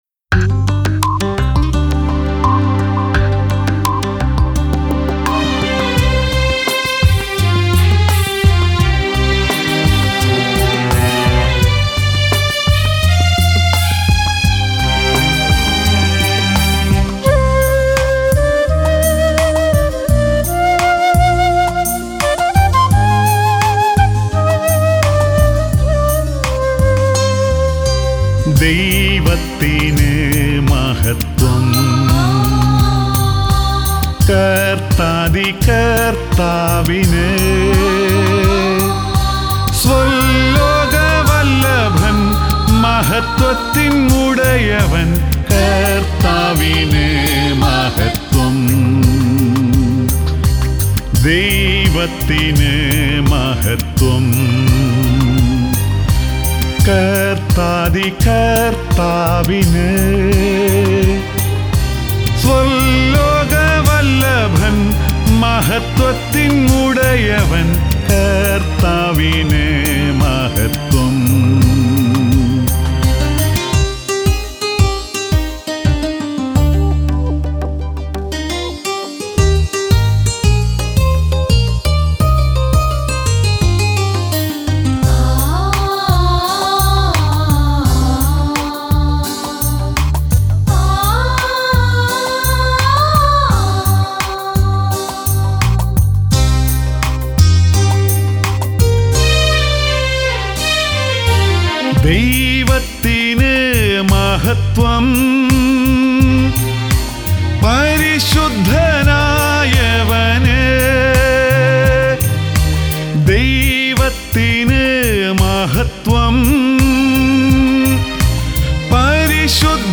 Keyboard Sequence
Rhythm Sequence
Flute
Tabala & Percussion